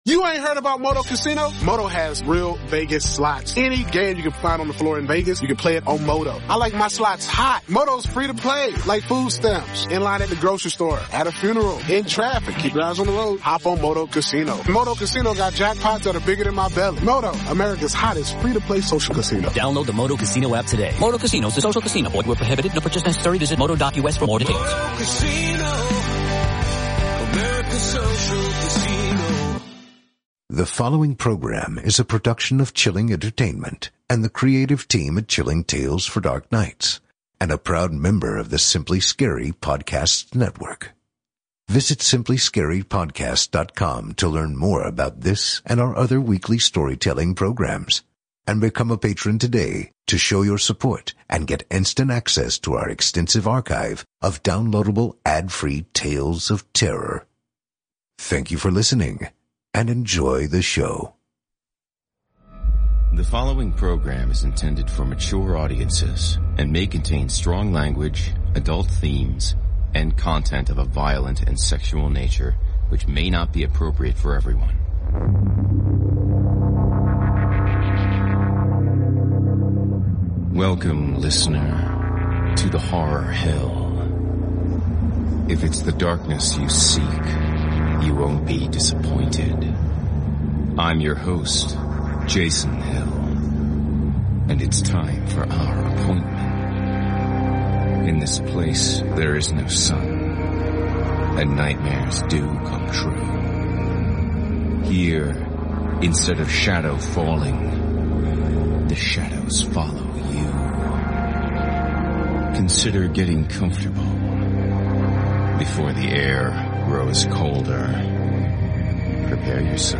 performed by host and narrator